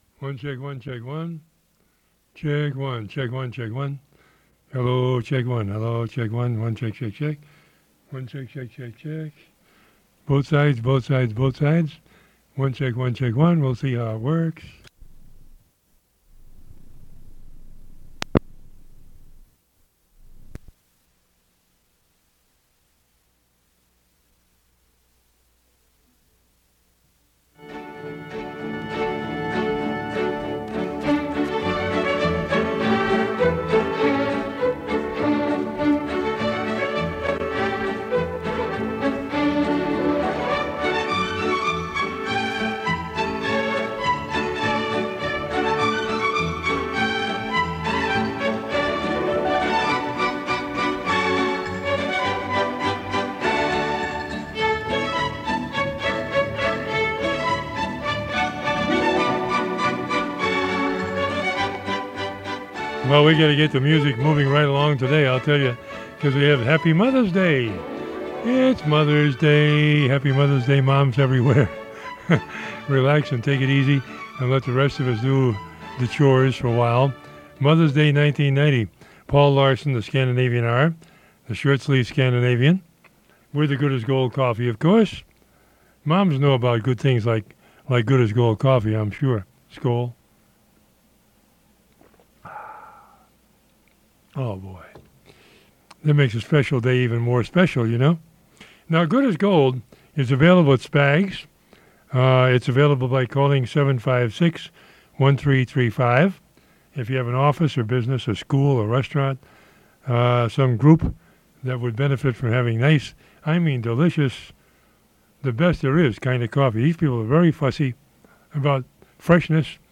This is a recording of Scandanavian Hour from Mother's Day 1990, which happened on May 13th, 1990. It's the whole program from a 10 inch reel-to-reel tape.